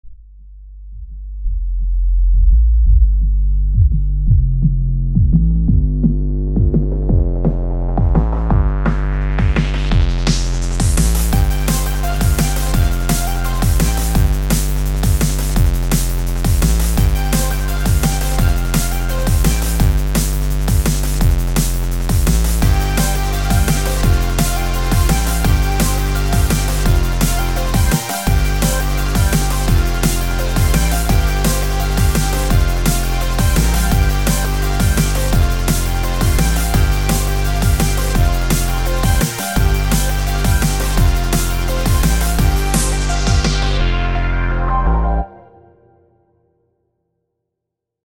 Just messin around in Flstudio havin fun with 170 BPM and some breakbeat =D